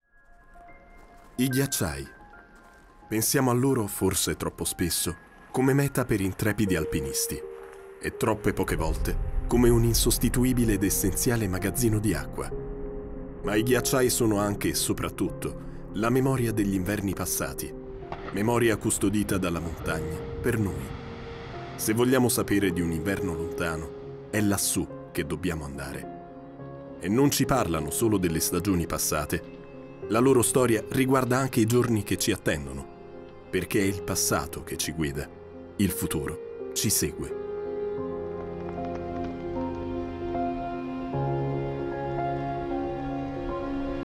Profonde, Enjouée, Cool
Vidéo explicative
Jeune acteur vocal ayant de l'expérience dans le doublage.